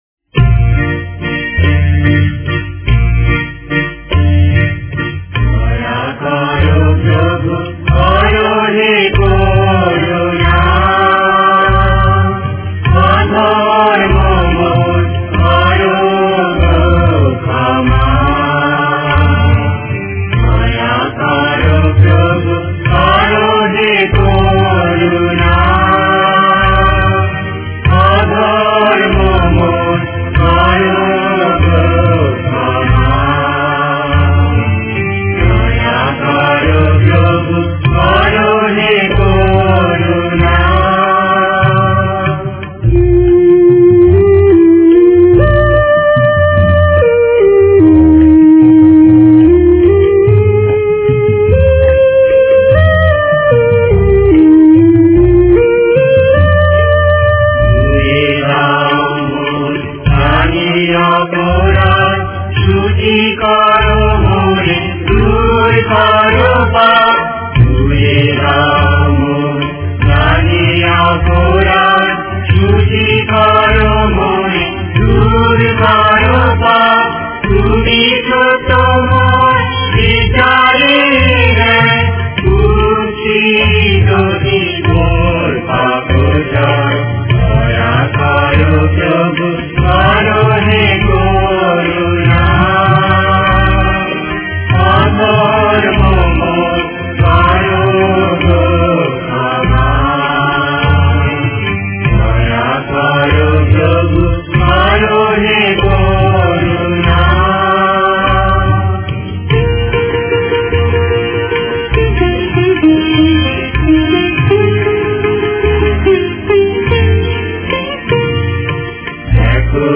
Directory Listing of mp3files/Bengali/Devotional Hymns/Good Friday/ (Bengali Archive)